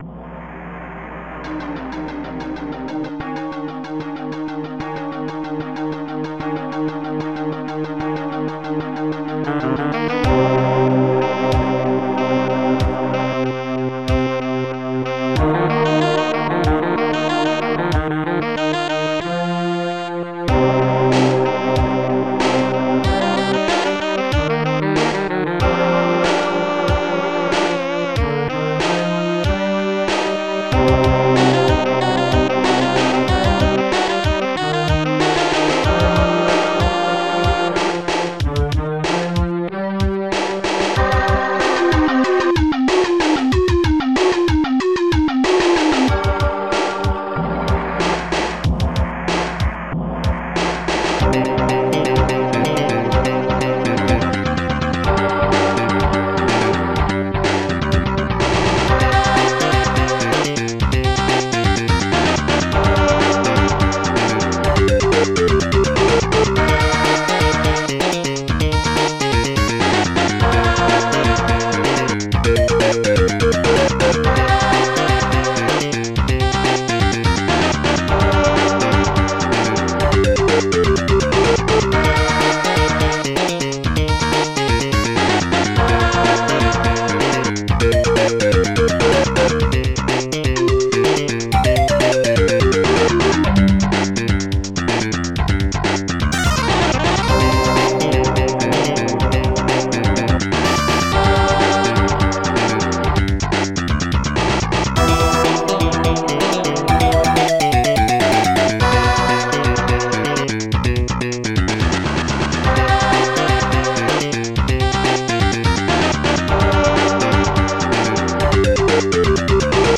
gong
tenor sax-long